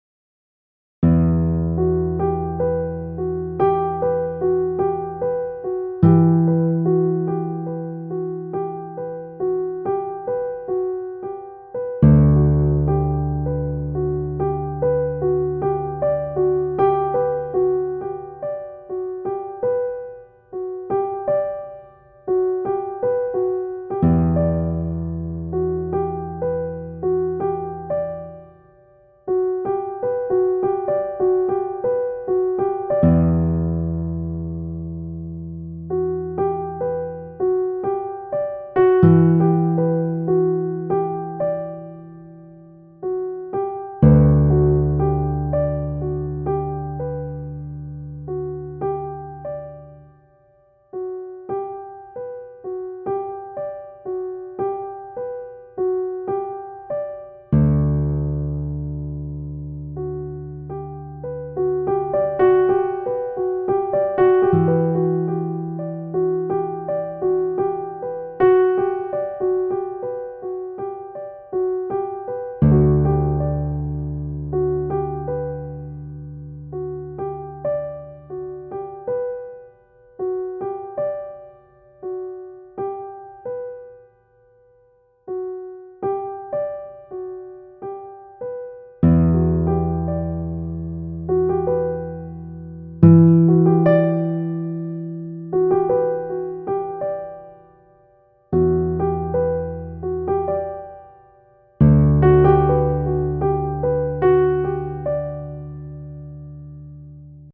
Intime Classique